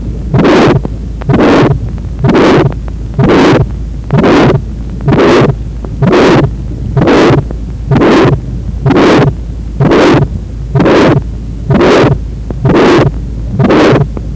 ผู้ป่วยอายุ 2 ปี ไม่มีอาการเขียว เวลาร้องเขียวจะมาก การตรวจร่างกายพบ acyanosis, normal Sl , Split S2 with S2P decrease,
ร่วมกับ pulmonary ejection click, grade 4/6 midsystolic ejection murmur at ULSB